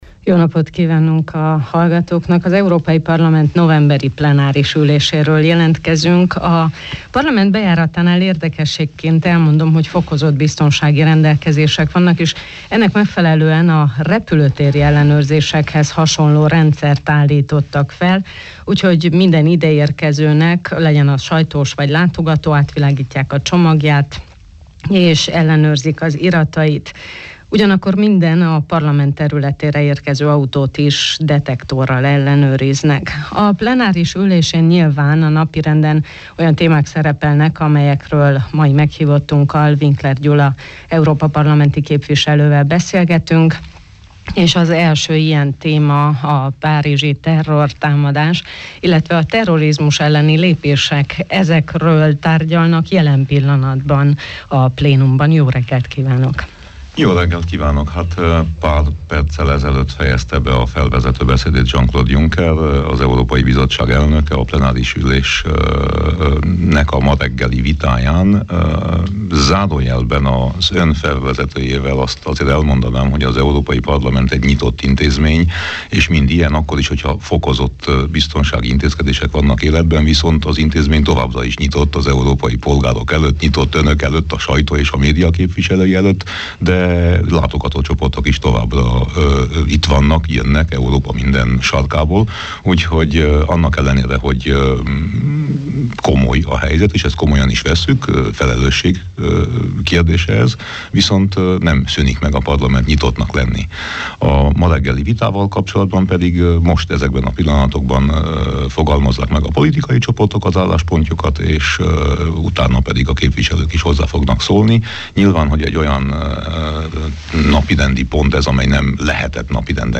Schengen nélkül nincs Európai Unió – hangsúlyozta a ma reggeli Miben segíthetünk? műsorban Winkler Gyula EP képviselő. A beszélgetést a strasbourgi kihelyezett stúdióból közvetítettük.